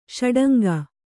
♪ ṣaḍanga